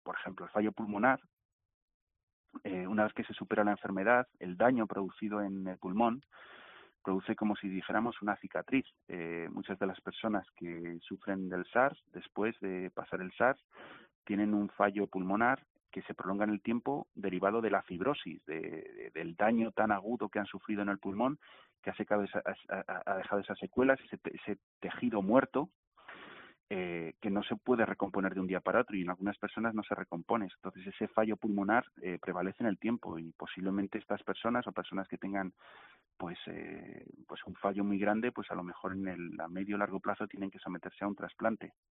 virólogo